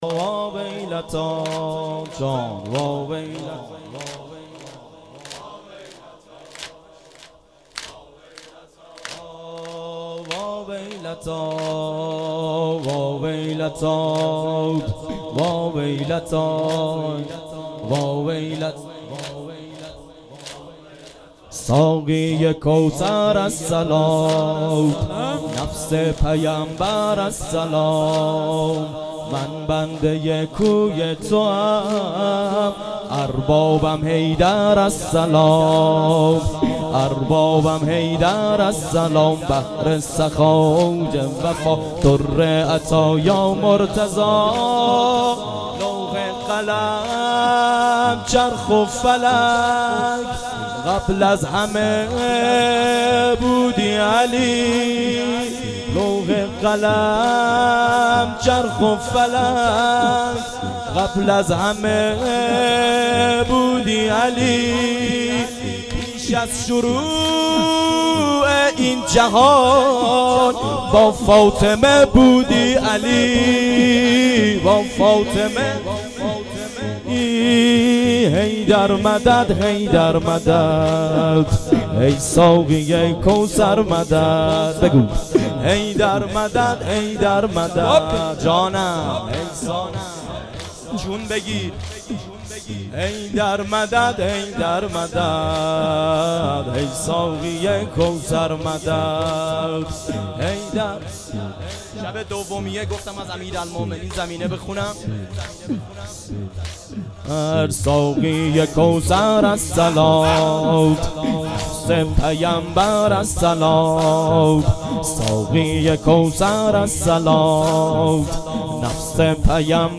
نفس پیامبر .... زمینه
زمینه (نفس پیامبر) شب دوم ...هیئت جوانان یاابالفضل علیه السلام همدان